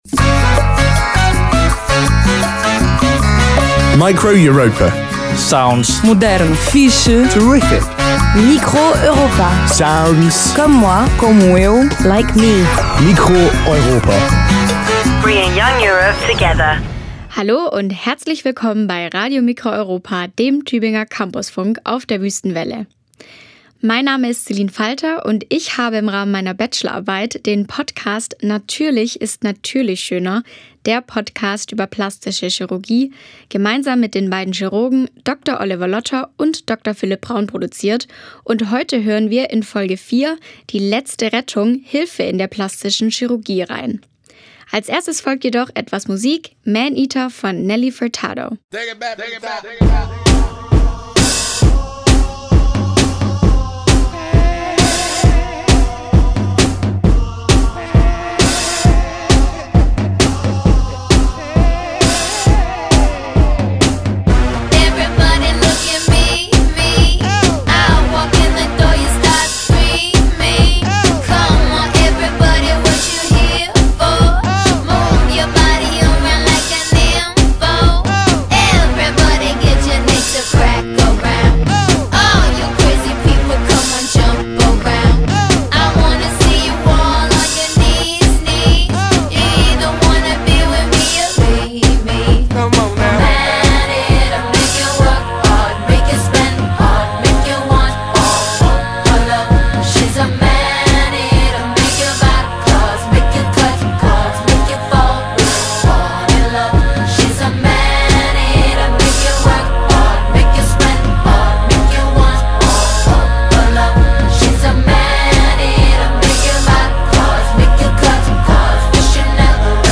Form: Live-Aufzeichnung, geschnitten